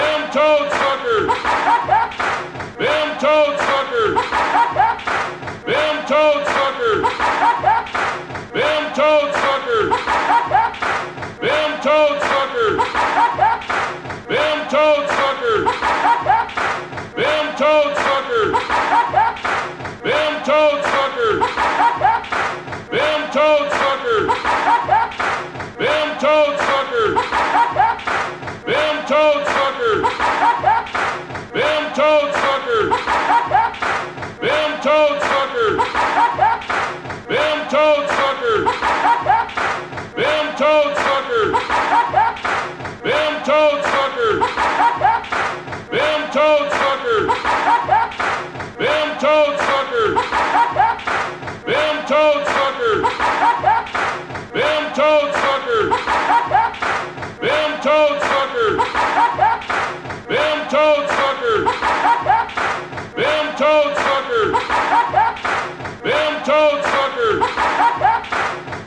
A loop of "THEM TOAD SUCKERS!" for over a minute